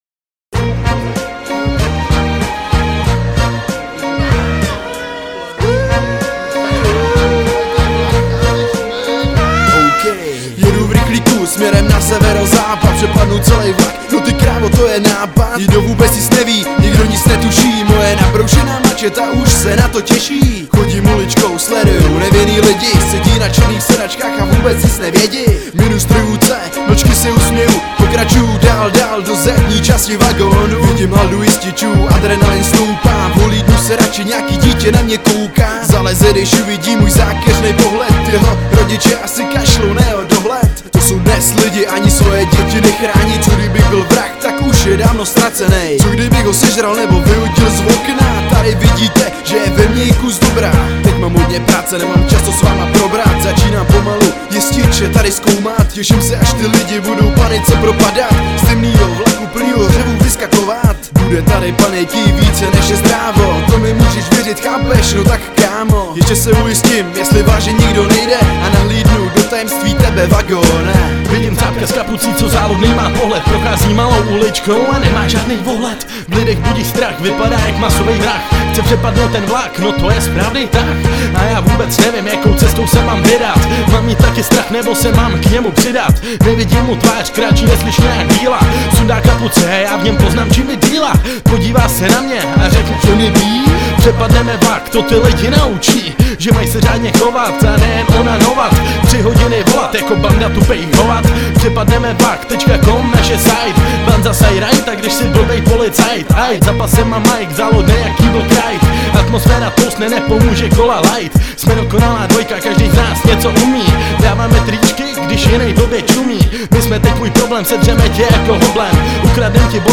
8 Styl: Hip-Hop Rok